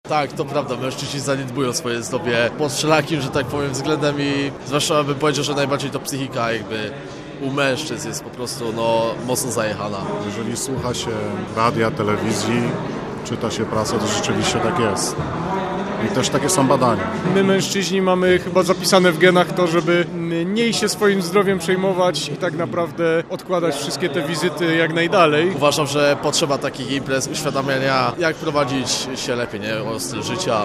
Uczestnicy wydarzenia, z którymi rozmawiał nasz reporter, przyznają, że jest pewien problem z dbaniem o zdrowie.